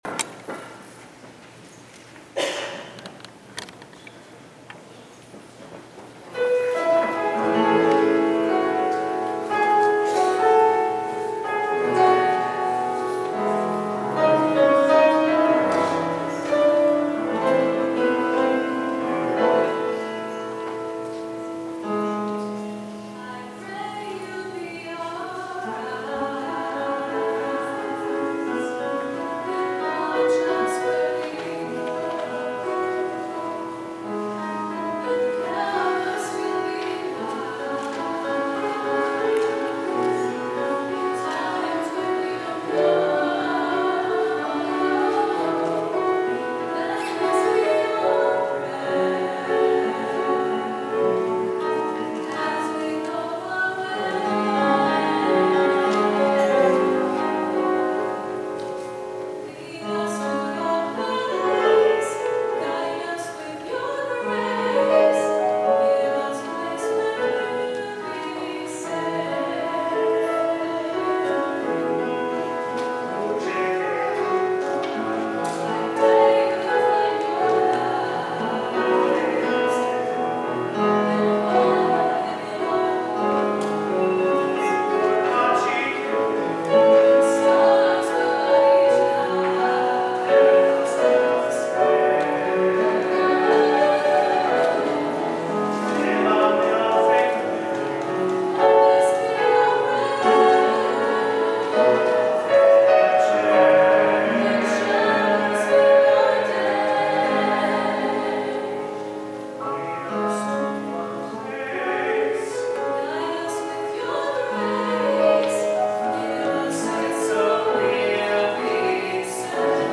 Following the Worship Service and the Anniversary Dinner, the day’s events Sunday concluded with a Music Celebration service.
Various vocals and instrumentals, performed by people from the youngest to the more mature were featured.